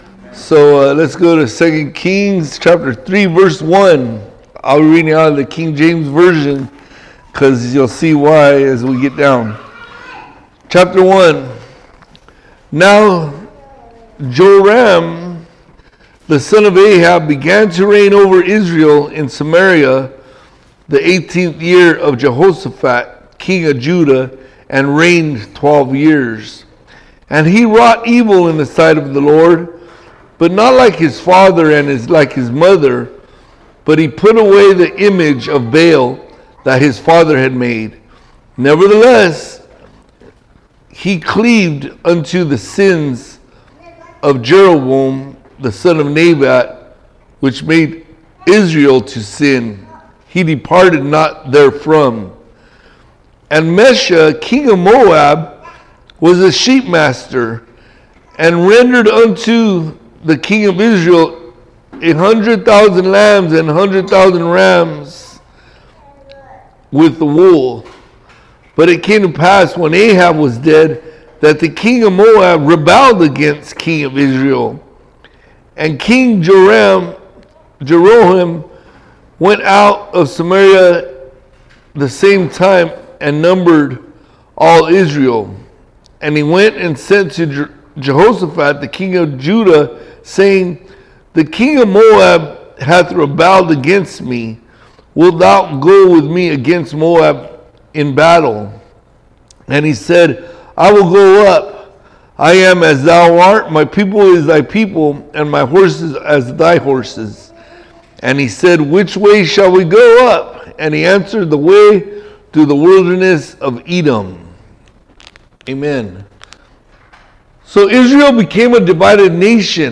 All Sermons Ditches September 4